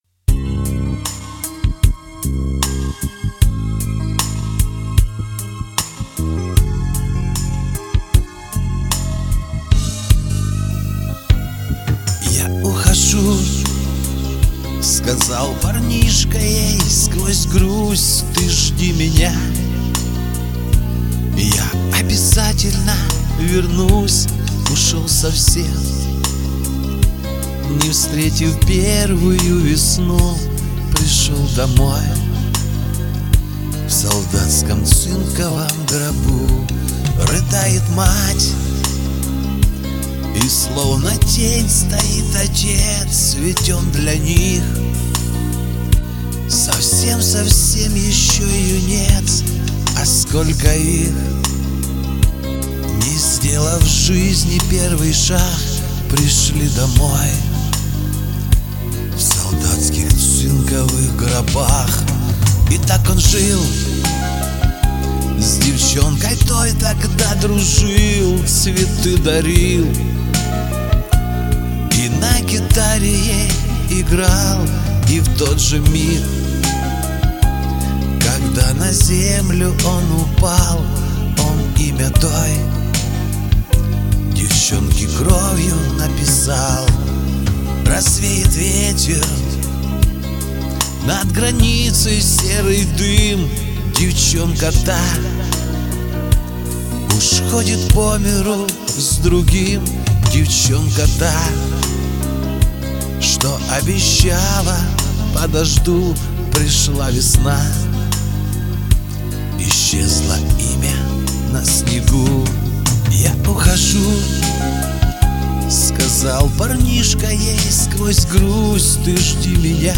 Справа много эха!